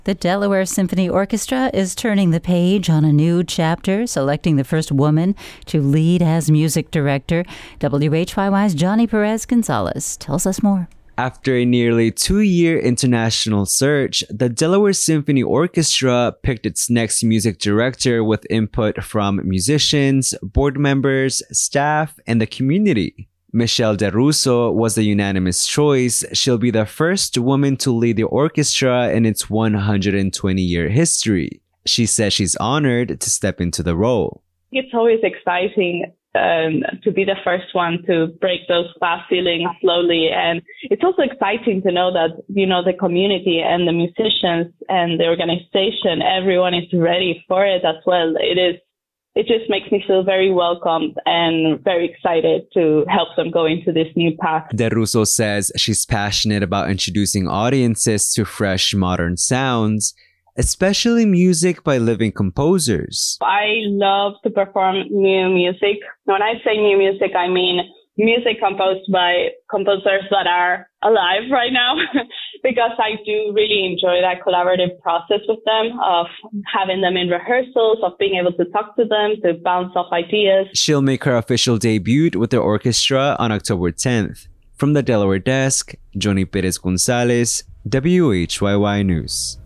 First Extra: Mayoral Debate - WHYY
WHYY and The News Journal will hold 4 debates among the democratic candidates for mayor of Wilmington. In this first debate held March 24th the topic was economic development.